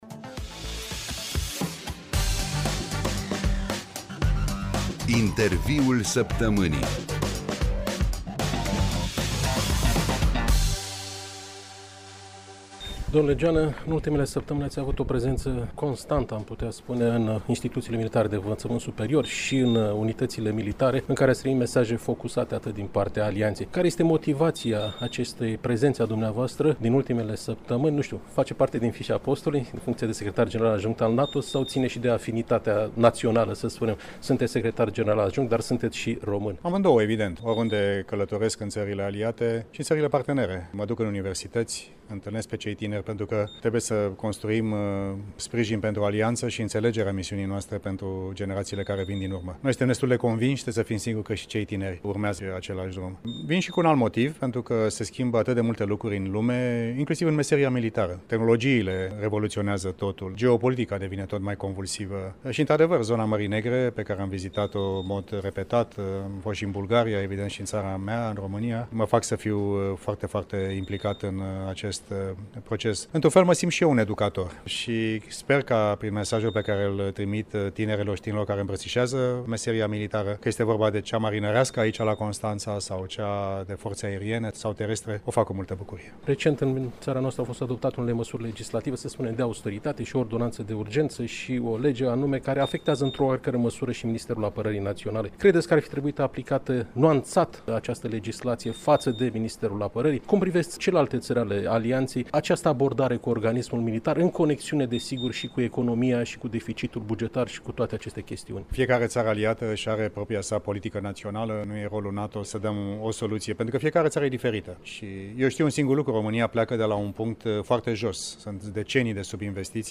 Interviul-Saptamanii.mp3